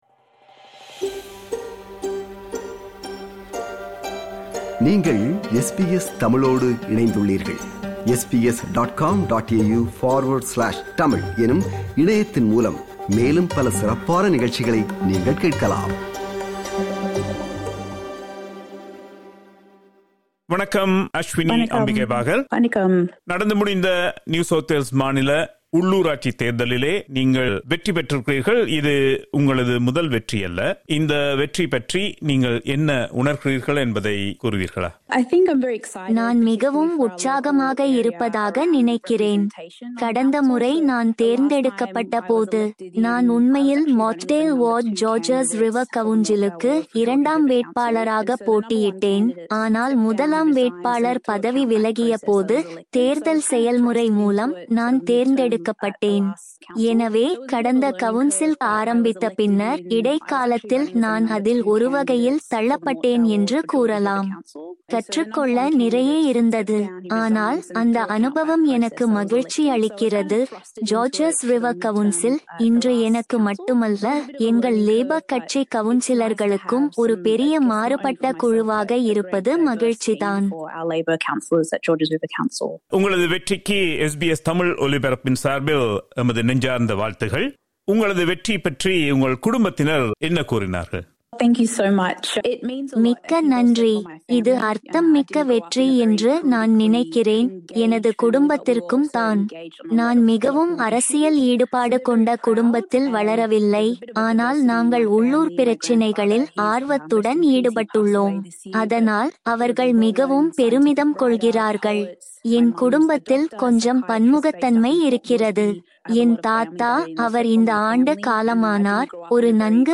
in a conversation